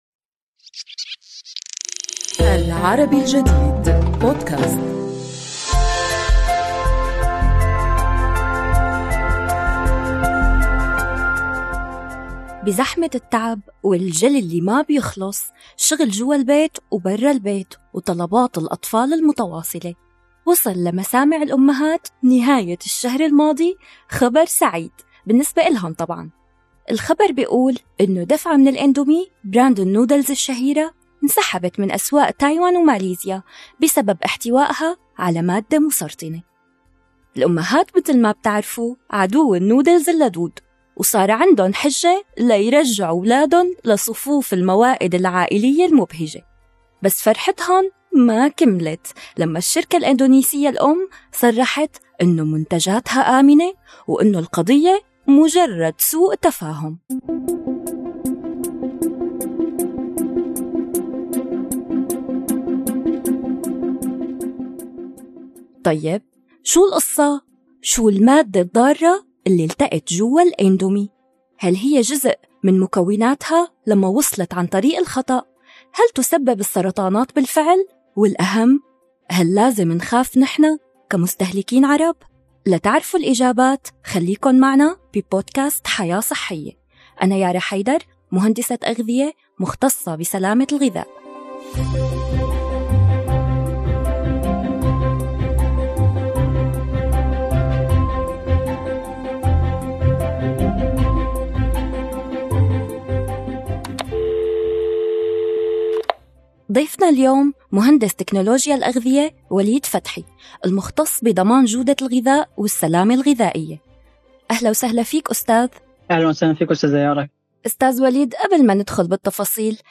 يجيب عن هذه الأسئلة وغيرها ضيفنا في هذه الحلقة، مهندس تكنولوجيا الأغذية